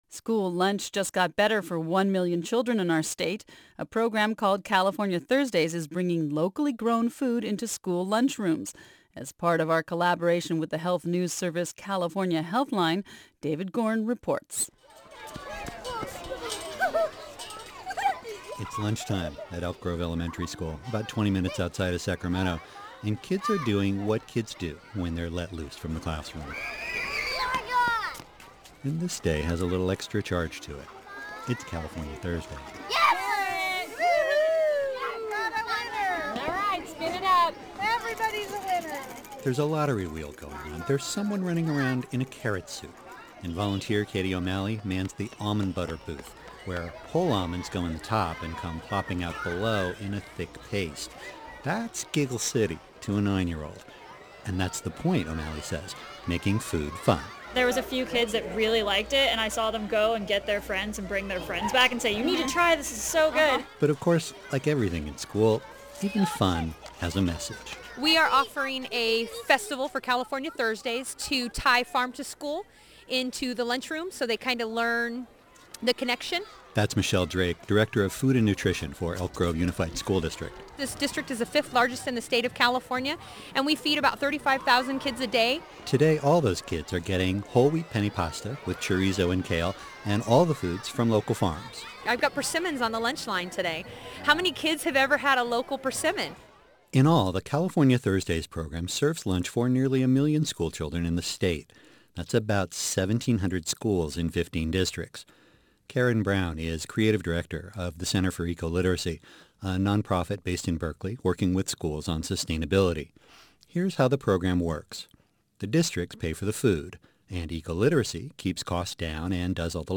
The report includes comments from:
Audio Report Insight Multimedia